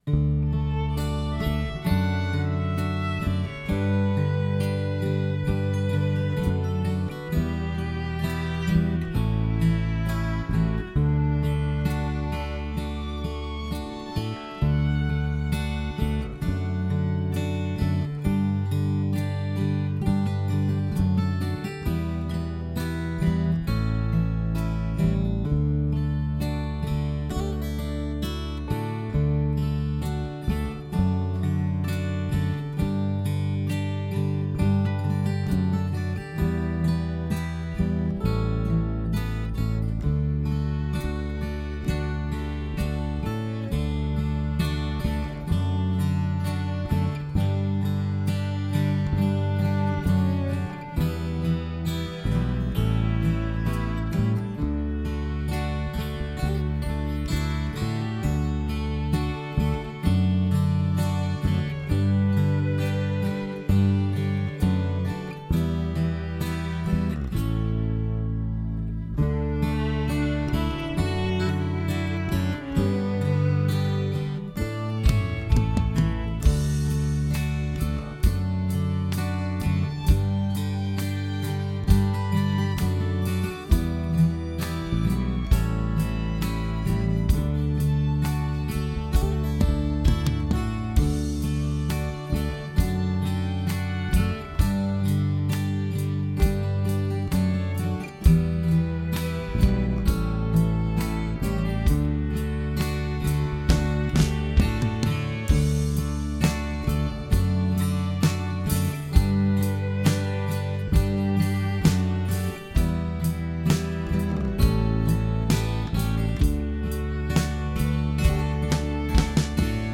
Key of D - Track Only - No Vocal